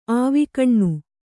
♪ āvikaṇṇu